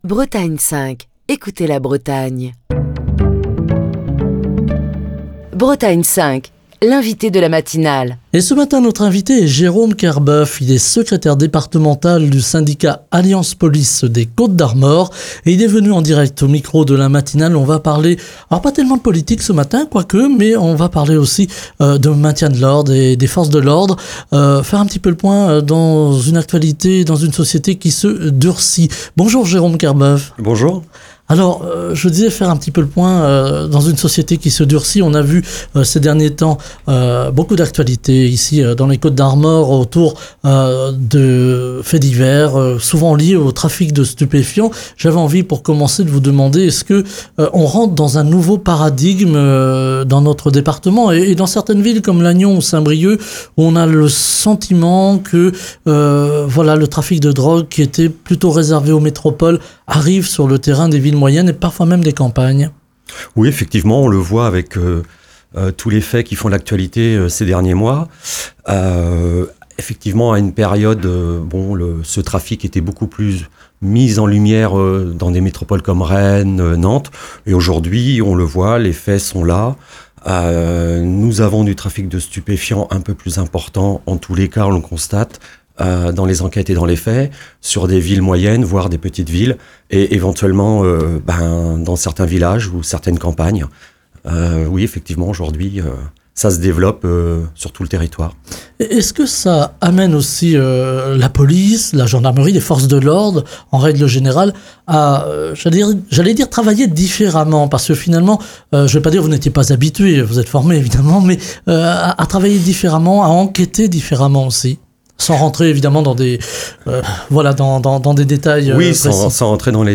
était l'invité de la matinale de Bretagne 5, ce vendredi. À cette occasion, il a abordé les nombreux défis auxquels la police est confrontée dans sa lutte contre le narcotrafic, dans un contexte marqué par une escalade de la violence.